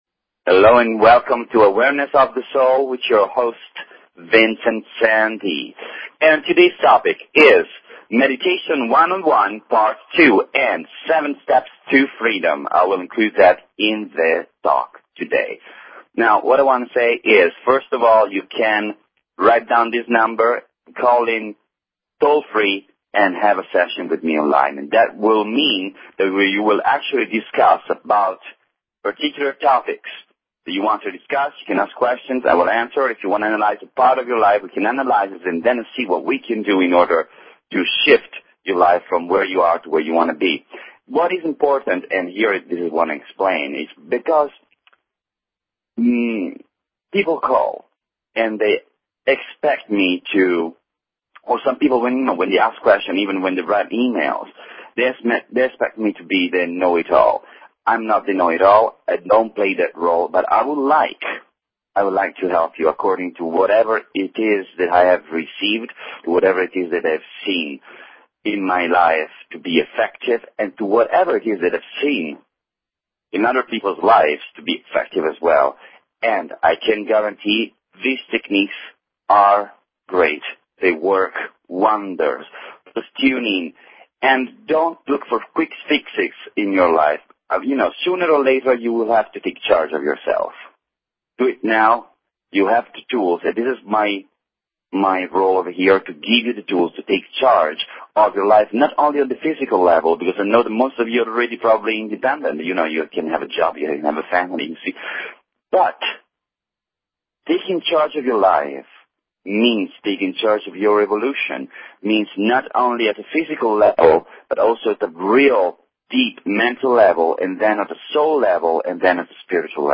Talk Show Episode, Audio Podcast, Awareness_of_the_Soul and Courtesy of BBS Radio on , show guests , about , categorized as